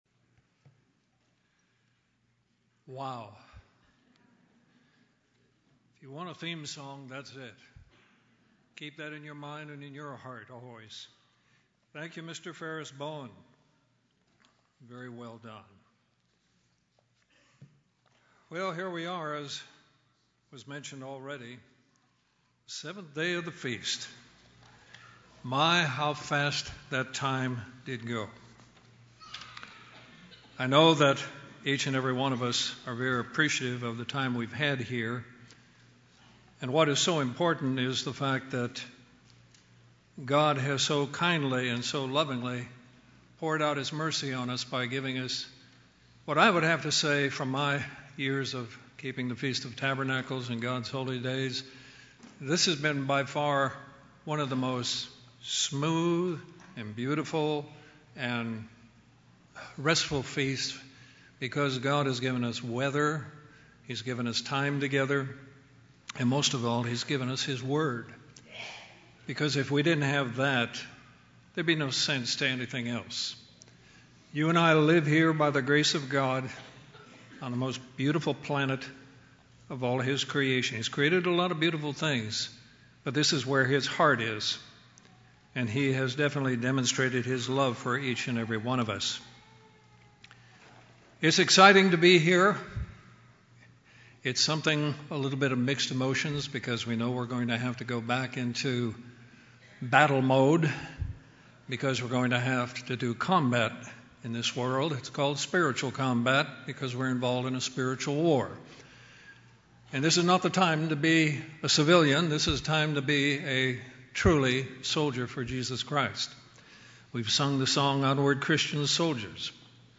This sermon was given at the Panama City Beach, Florida 2016 Feast site.